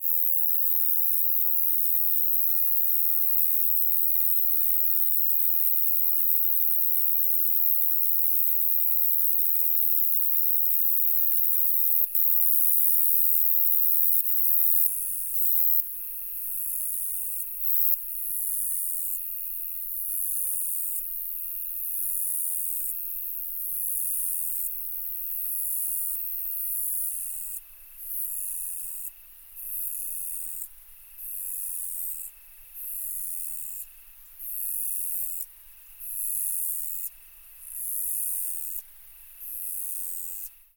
Звуки летнего вечера
Здесь вы найдете пение сверчков, легкий ветерок в листве, отдаленные голоса птиц и другие успокаивающие звуки.
Шепот вечернего заката в поле